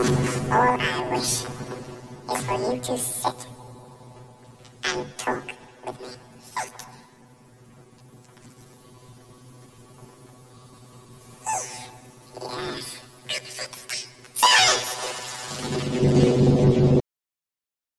For all experiments, I used a sound clip from one of my favorite movies “Legend” where Tim Curry plays the devil, and Tom Cruise and Mia Sara are the main characters fighting him.
Here is a higher pitched sound that plays back each grain 2.5 times faster (1.0 / 0.4).
out_c_higher.mp3